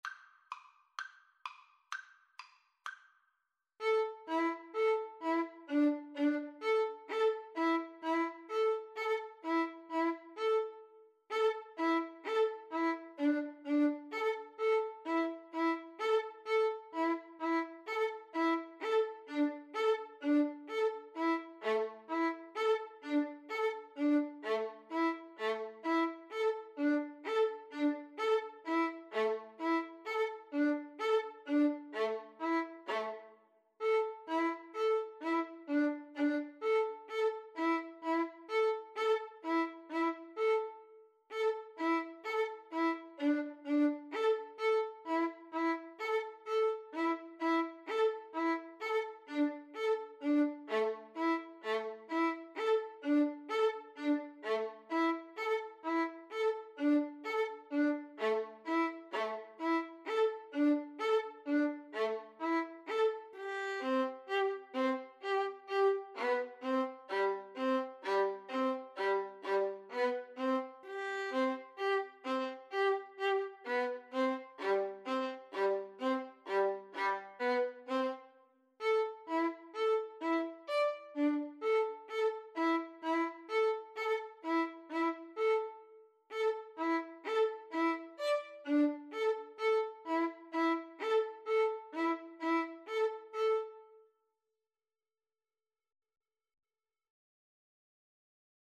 A traditional Neapolitan Italian Tarantella associated with the Southern Italian town of Naples.
A minor (Sounding Pitch) (View more A minor Music for Violin Duet )
6/8 (View more 6/8 Music)